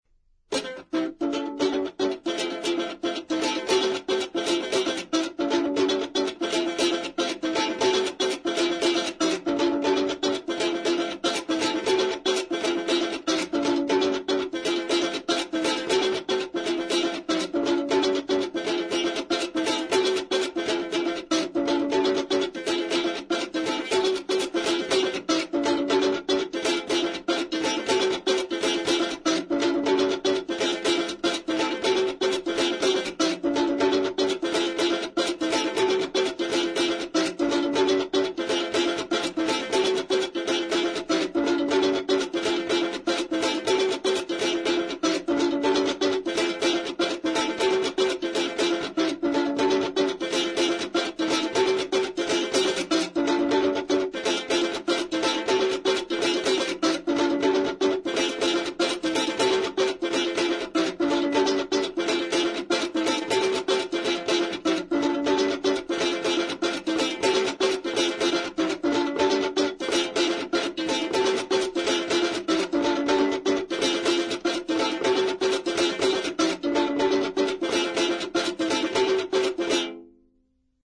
Folk music South Africa
Folk songs, Xhosa South Africa
Stringed instrument music South Africa
Africa South Africa Lumko, Eastern Cape sa
field recordings
Indigenous music.